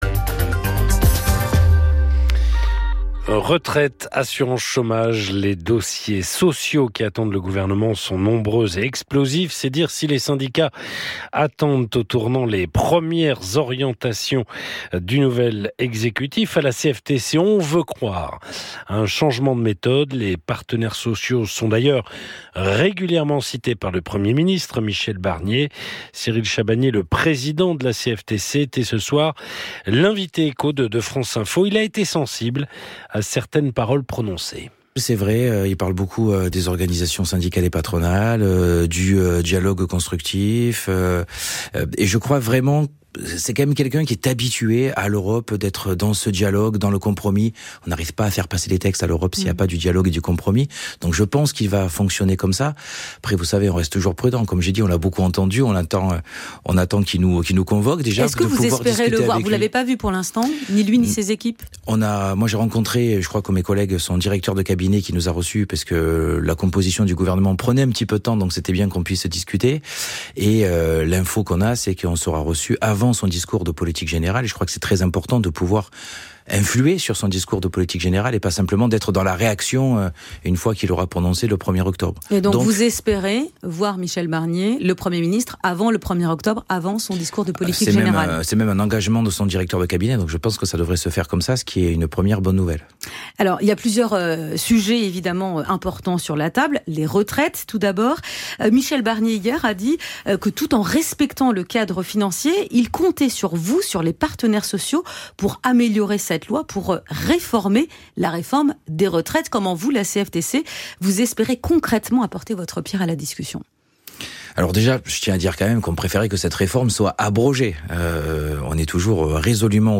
Alors que la CFTC sera reçue ce jeudi 26 septembre par Michel Barnier, son président, Cyril Chabanier, est revenu au micro de France Info sur les prochains enjeux et grandes échéances du dialogue social.
Retrouvez ici des extraits de son intervention: